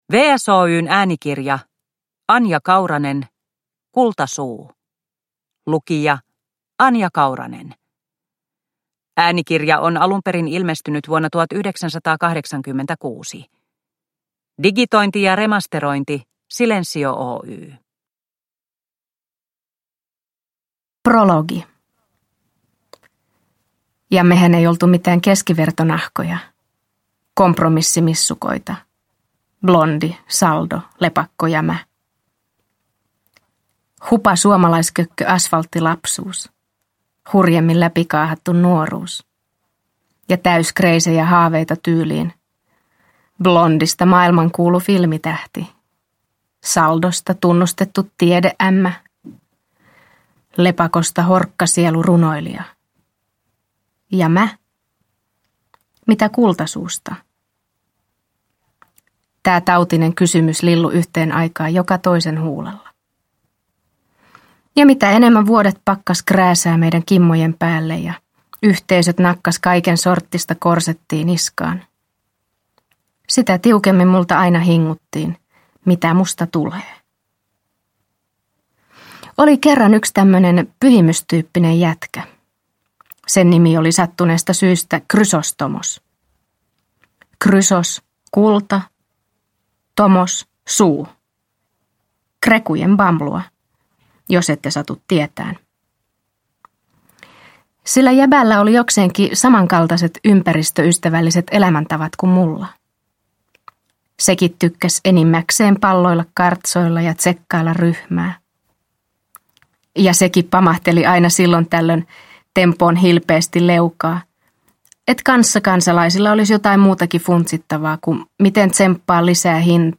Kultasuu – Ljudbok – Laddas ner
Uppläsare: Anja Kauranen